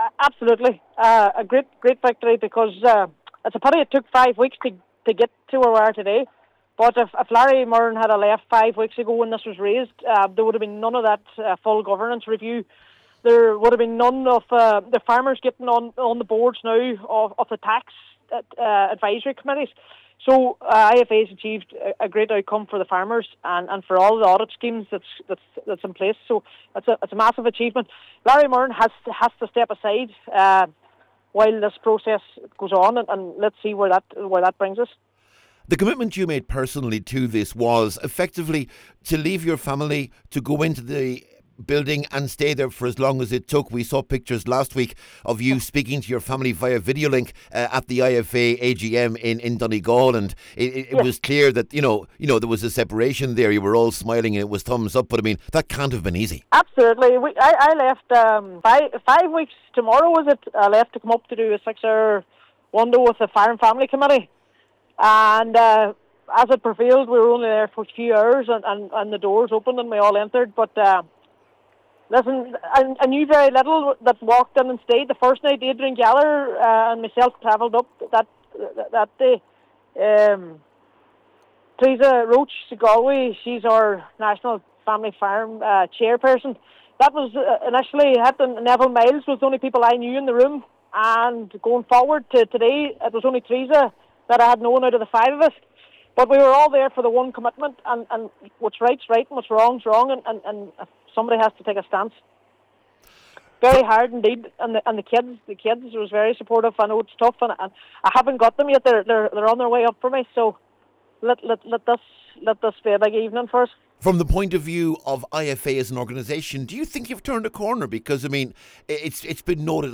Speaking to Highland Radio News tonight